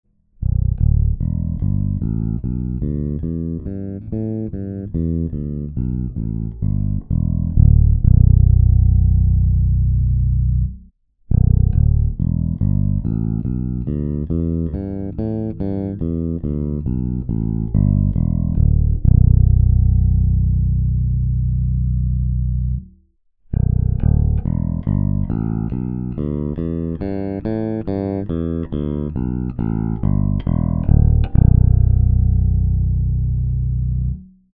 Im Vergleich zum passiven Signal sind beide viel basslastiger und dumpfer.
So dachte ich eigentlich auch immer - mein Ibanez SR1345 macht es aber so: Anhang anzeigen 707690 Zu hören ist erst der aktive VM3-Modus des Preamps, dann der aktive BQ3-Modus des gleichen Preamps, dann der passive Sound (nur auf die gleiche Lautstärke gebracht).